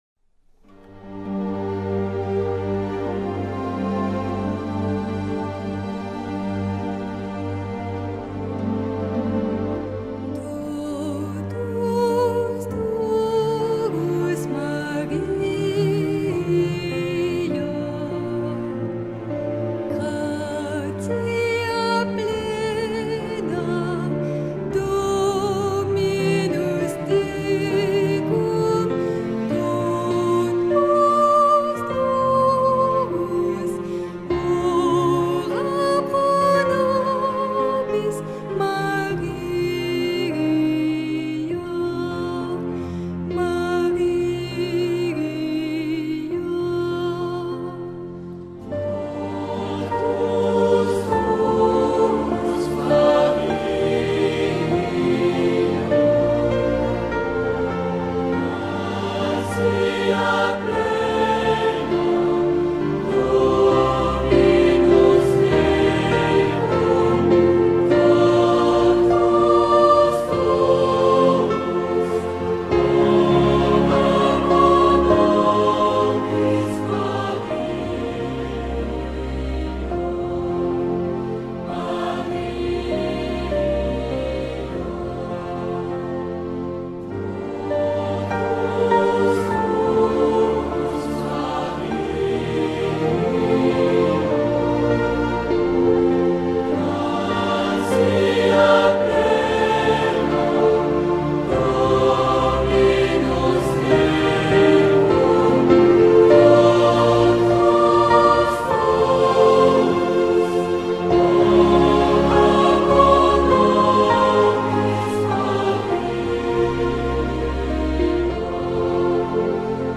Meditativa